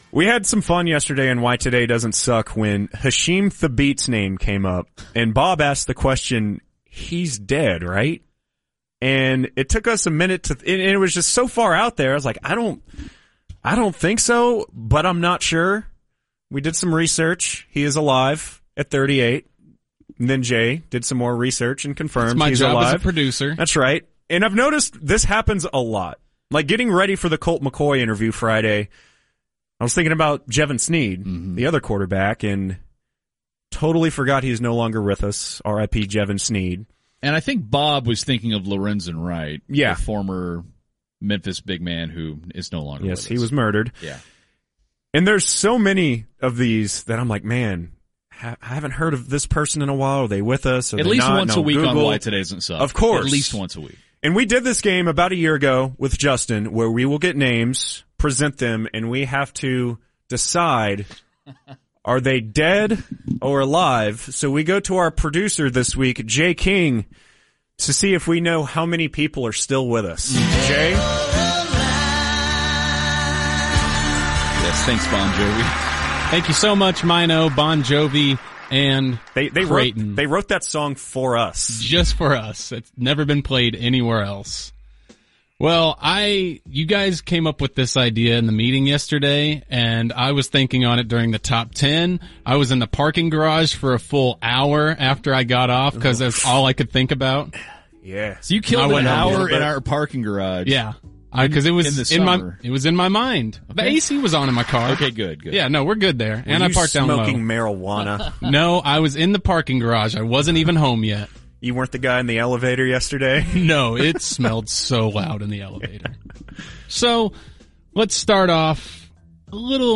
A new game show